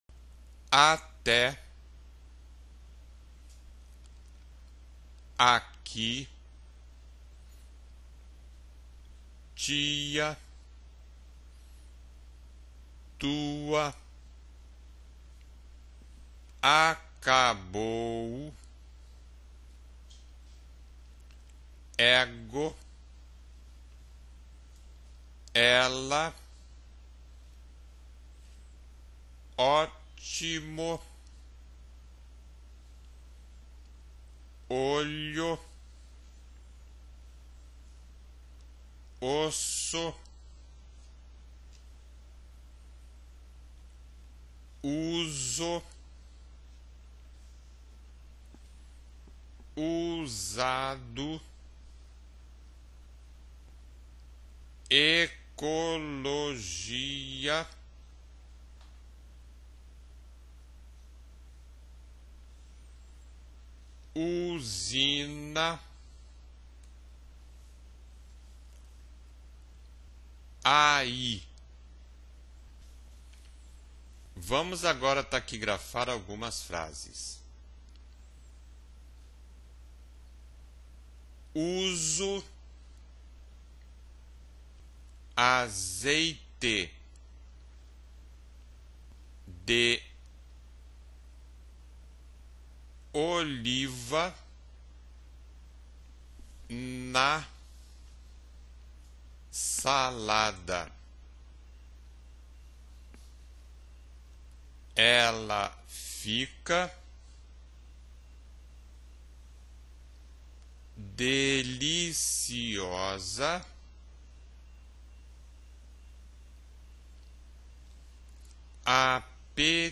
Ditado para escutar e taquigrafar.
ditado_07.wma